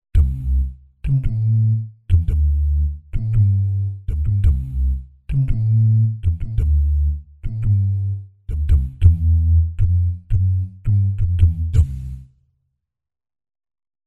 Index of /90_sSampleCDs/Spectrasonics Vocal Planet CD6 - Groove Control/Soundfinder/VP MVP Soundfinder Files/ 4. Jazz/2. Jazz Bass Multisamples